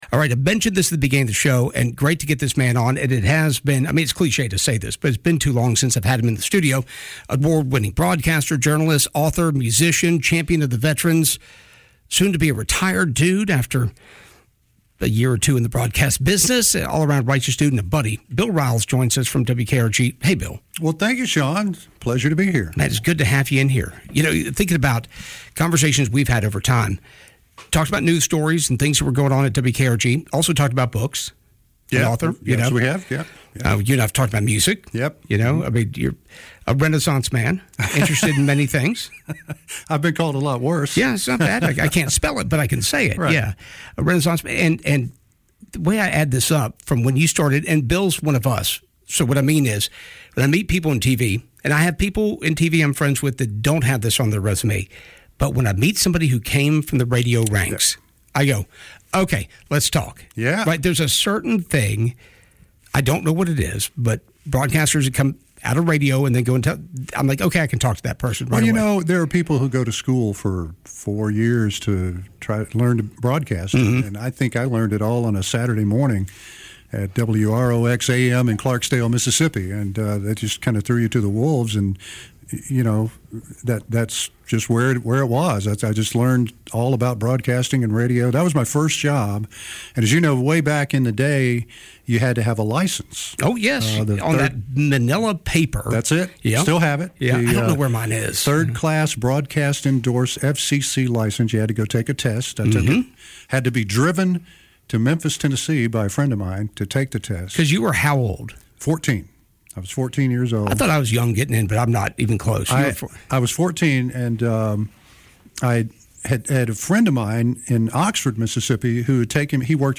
The conversation dives into his creative pursuits—from crime novels to banjo picking —and his unwavering commitment to veterans , including his emotional experience with Honor Flight South Alabama . It’s funny, nostalgic, and deeply inspiring.